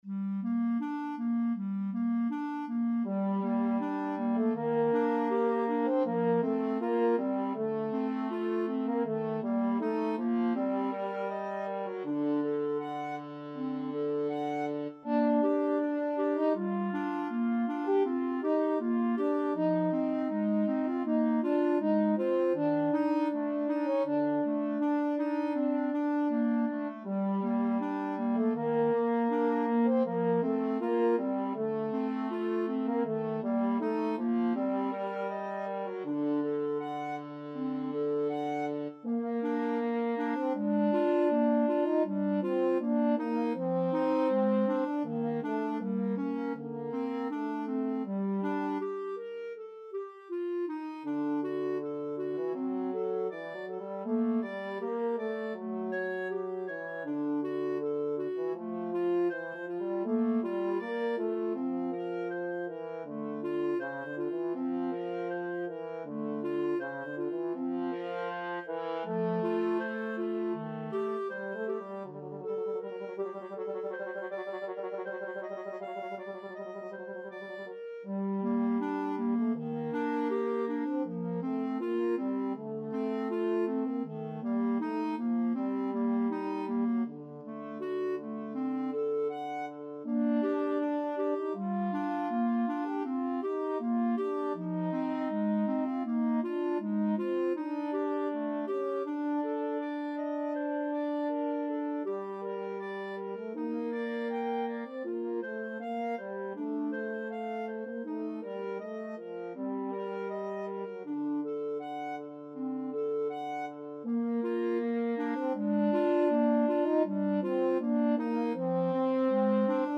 Free Sheet music for Clarinet-French Horn Duet
G minor (Sounding Pitch) (View more G minor Music for Clarinet-French Horn Duet )
Andante
Classical (View more Classical Clarinet-French Horn Duet Music)